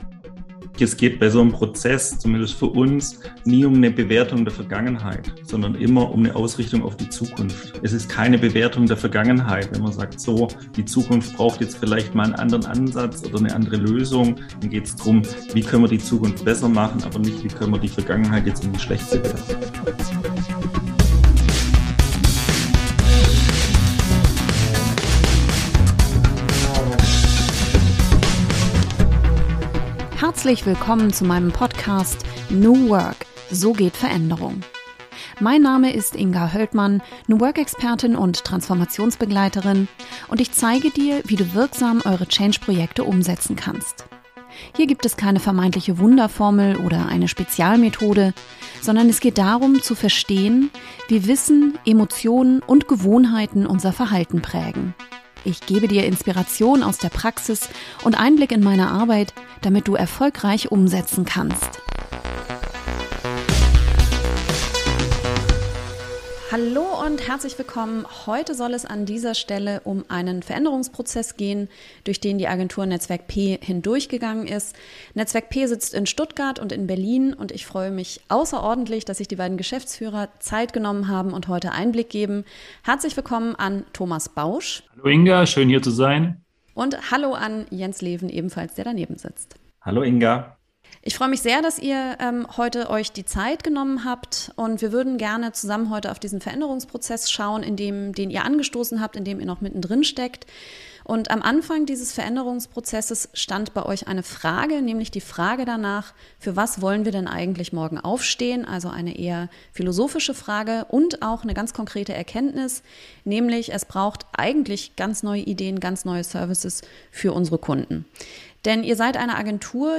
Interview | Veränderung aus eigener Kraft ~ New Work – so geht Veränderung Podcast